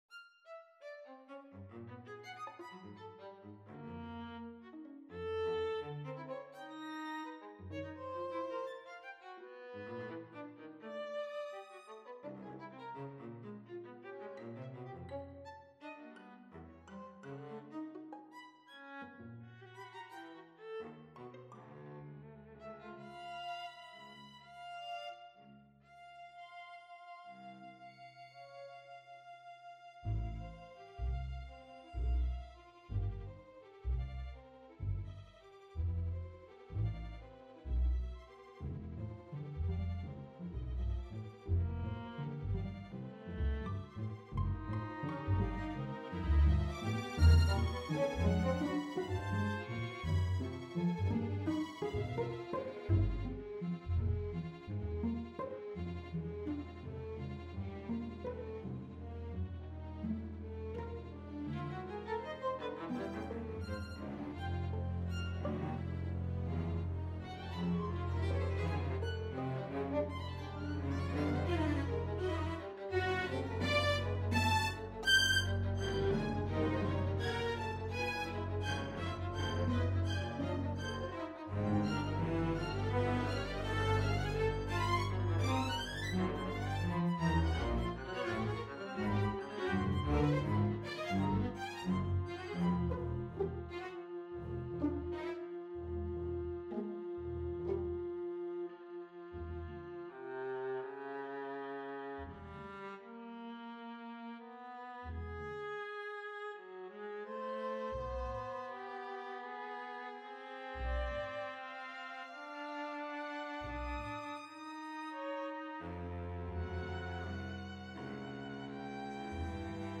Uraufführung Glosse für Streich-Kammerorchester
Glosse für Streich-Kammerorchester am 23.
glosse-fuer-streich-kammerorchester.mp3